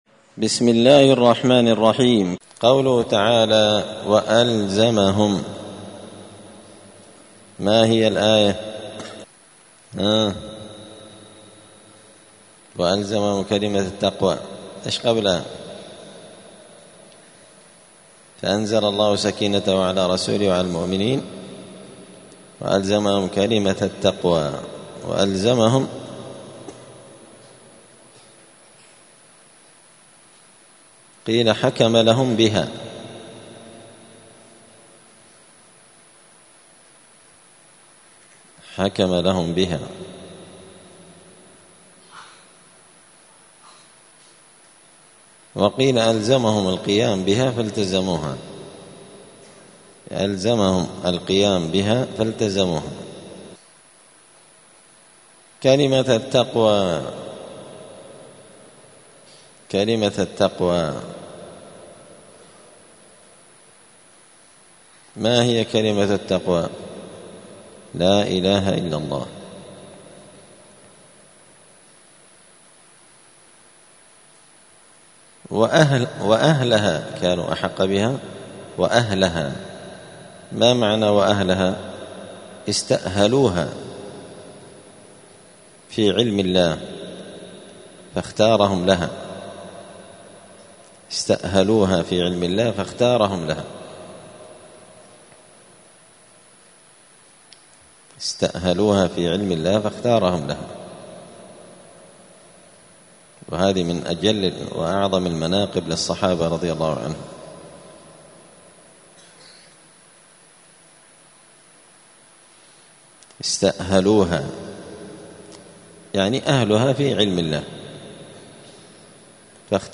الثلاثاء 3 جمادى الأولى 1446 هــــ | الدروس، دروس القران وعلومة، زبدة الأقوال في غريب كلام المتعال | شارك بتعليقك | 26 المشاهدات
دار الحديث السلفية بمسجد الفرقان قشن المهرة اليمن